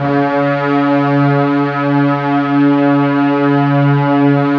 Index of /90_sSampleCDs/Roland - String Master Series/CMB_Combos 2/CMB_Mellow Pad
STR STRING00.wav